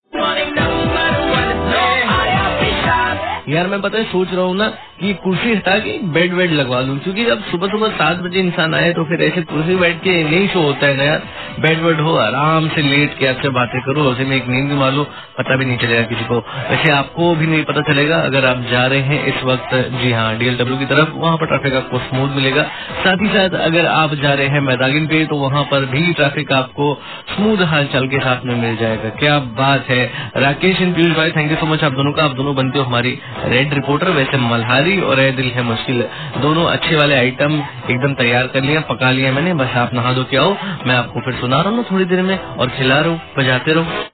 TRAFFIC UPDATE